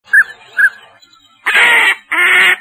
Les canards